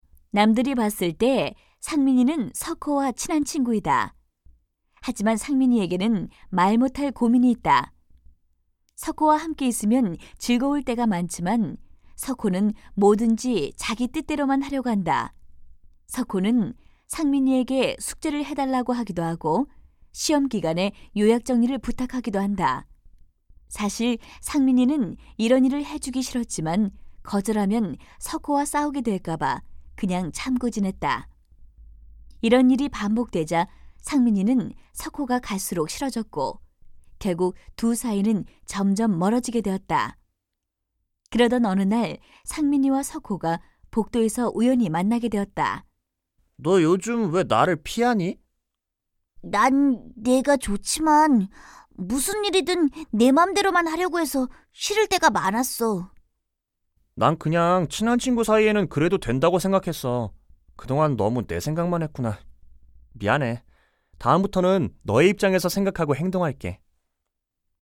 111쪽-내레이션.mp3